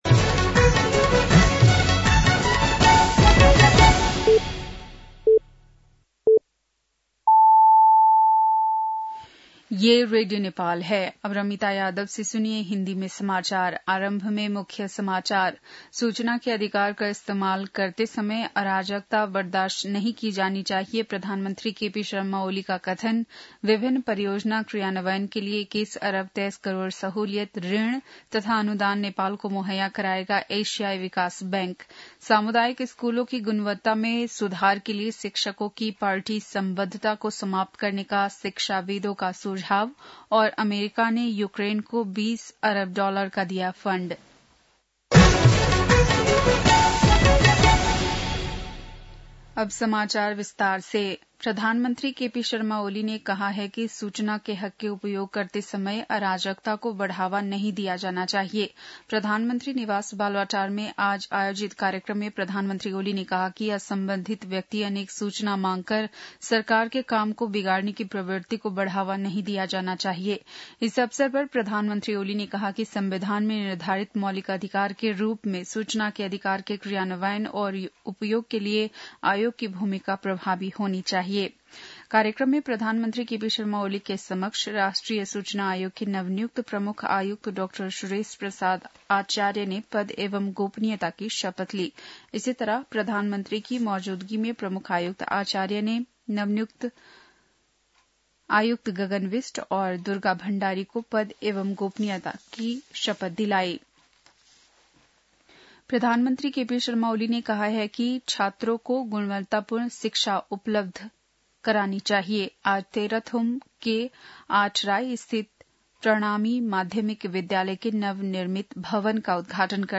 बेलुकी १० बजेको हिन्दी समाचार : २७ मंसिर , २०८१
10pm-News-8-26.mp3